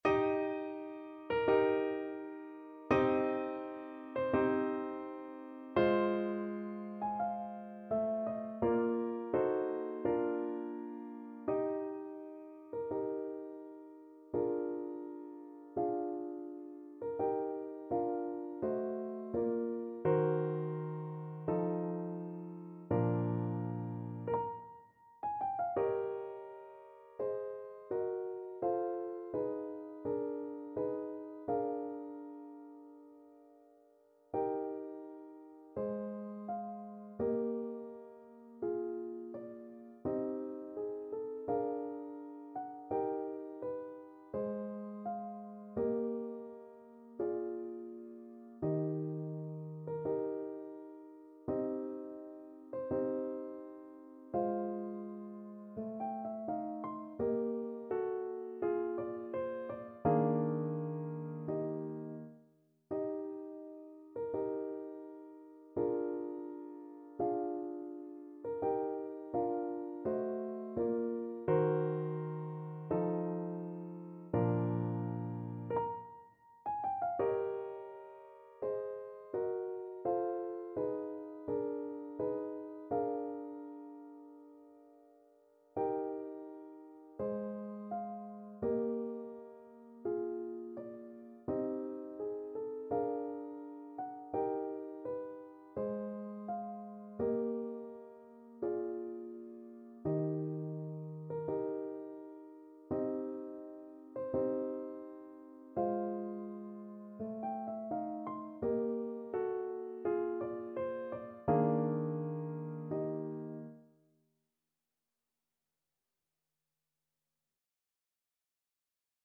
Play (or use space bar on your keyboard) Pause Music Playalong - Piano Accompaniment Playalong Band Accompaniment not yet available transpose reset tempo print settings full screen
Eb major (Sounding Pitch) (View more Eb major Music for Voice )
~ = 42 Sehr langsam
2/4 (View more 2/4 Music)
Classical (View more Classical Voice Music)